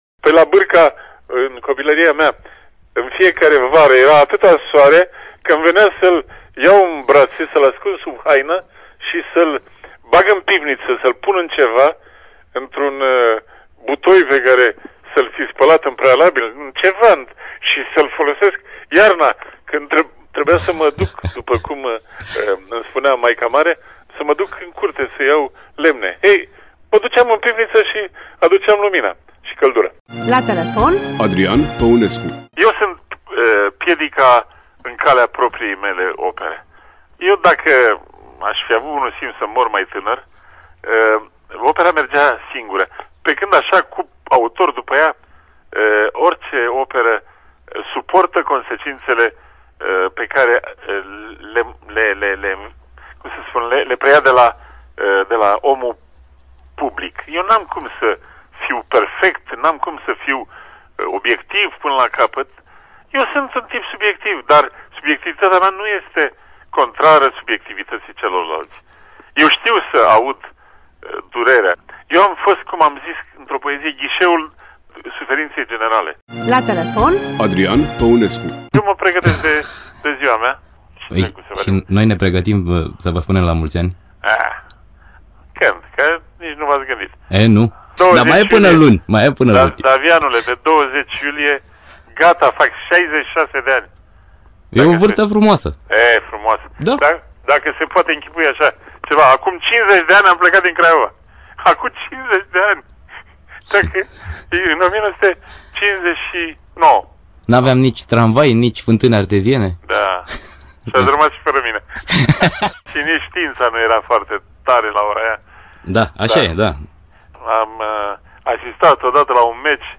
Adrian-Paunescu-la-Radio-Oltenia-Despre-copilarie-familie-poezie-si-fotbal.mp3